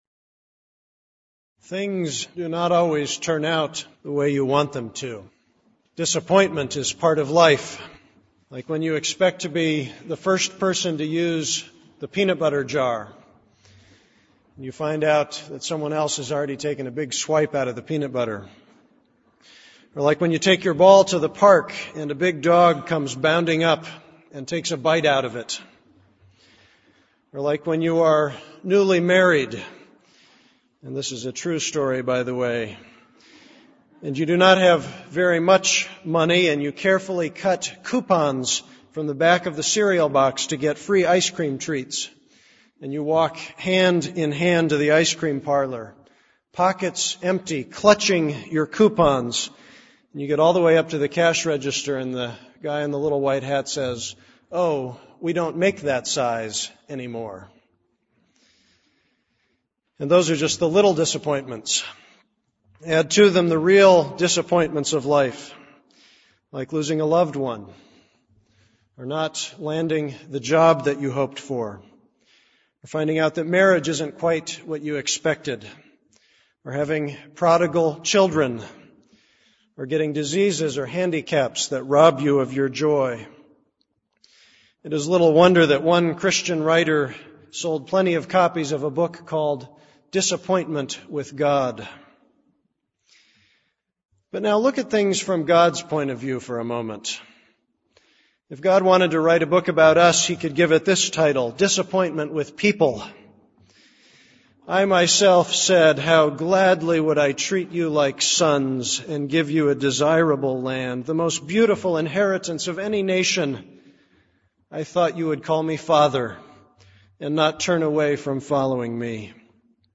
This is a sermon on Jeremiah 3:19-4:4.